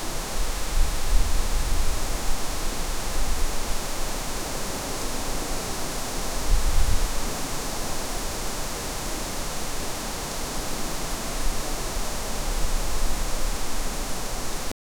forest wind branches
forest-wind-branches-lfyjeymy.wav